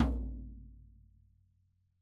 ropesnare-low-ns-main-vl3-rr2.mp3